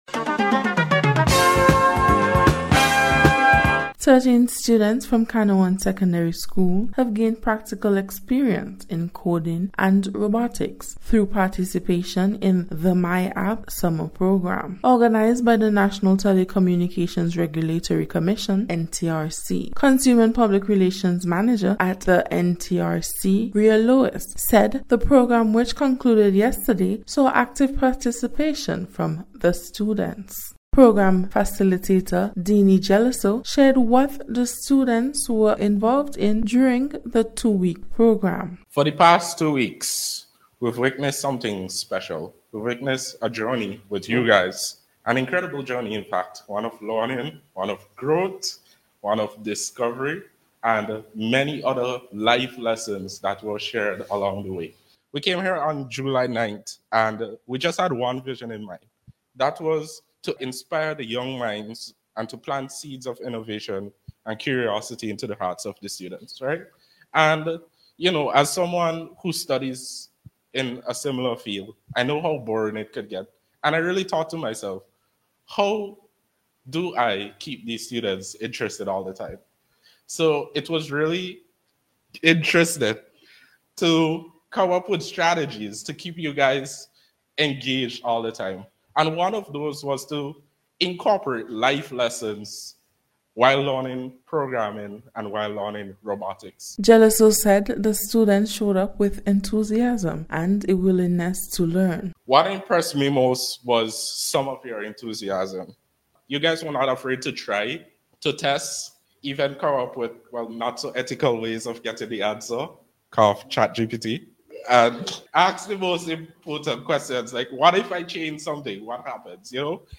NBC’s Special Report- Thursday 24th July,2025